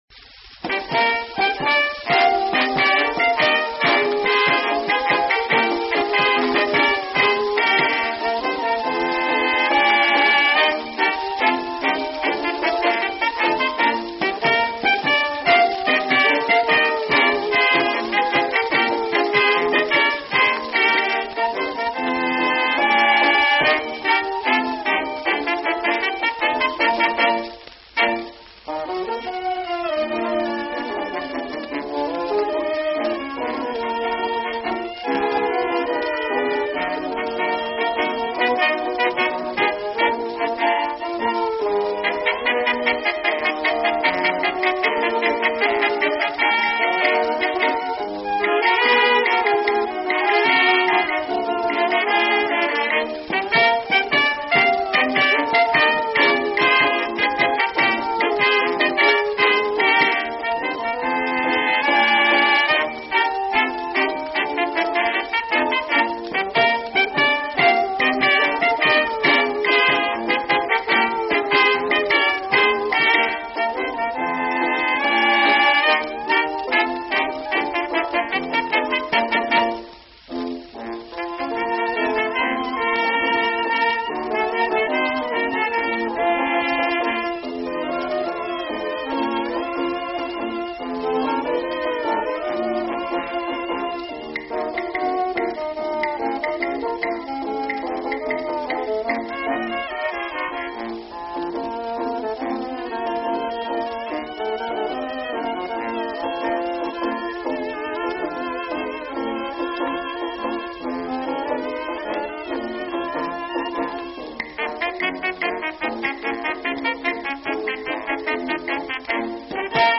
Conductor and Violinist